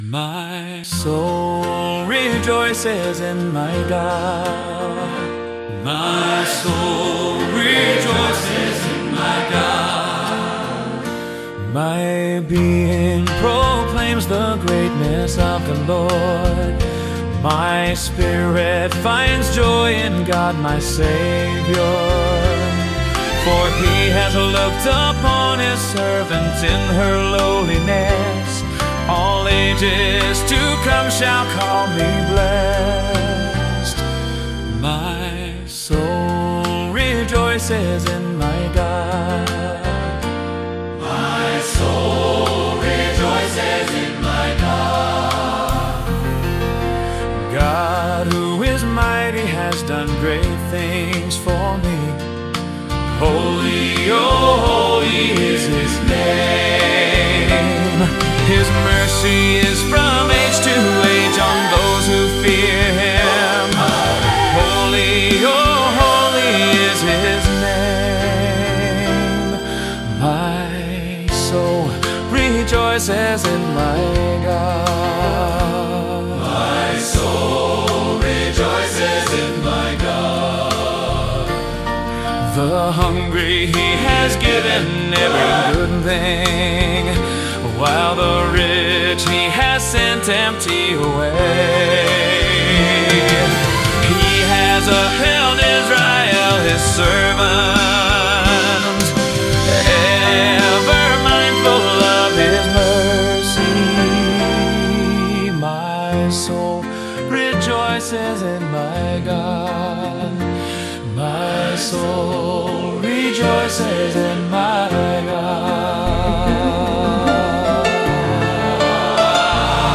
Mainstream